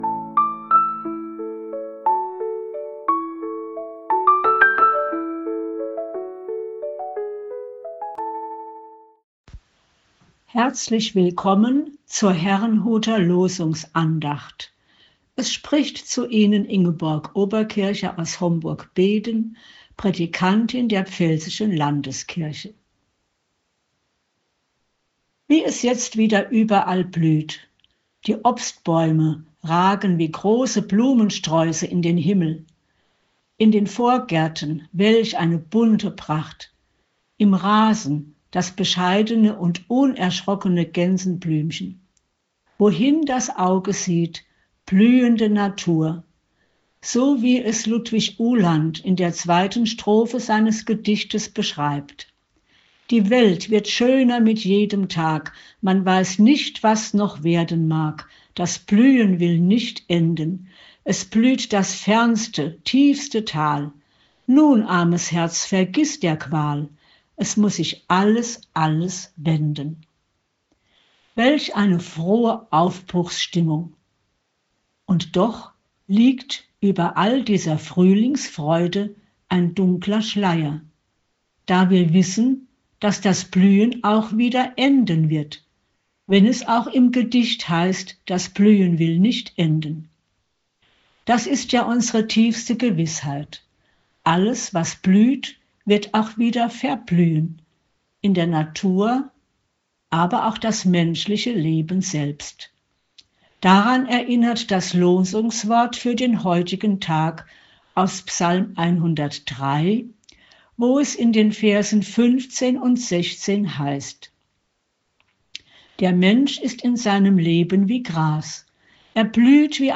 Losungsandacht für Mittwoch, 16.04.2025